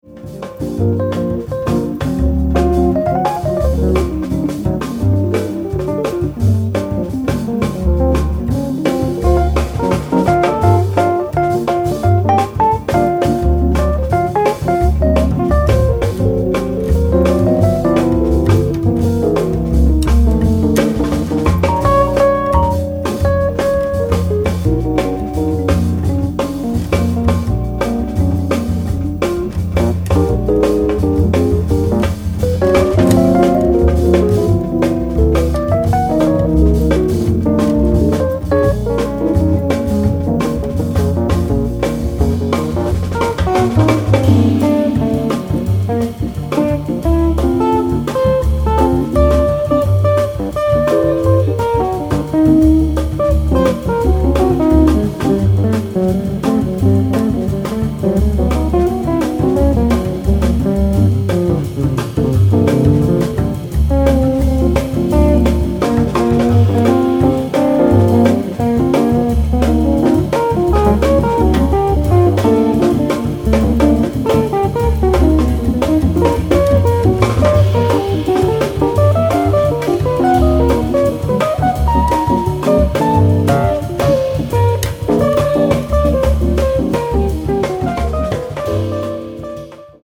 guitar
Fender Rhodes
bass
drums